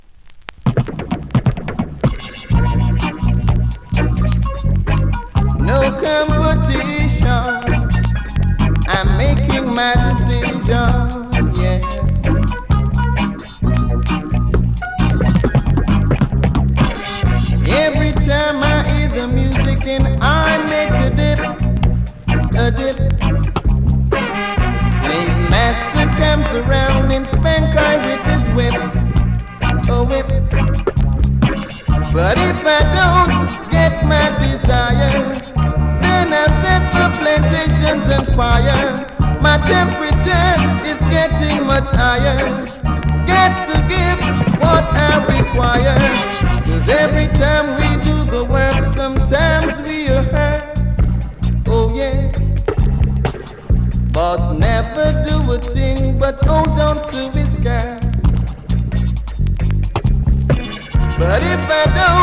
2026!! NEW IN!SKA〜REGGAE
スリキズ、ノイズそこそこありますが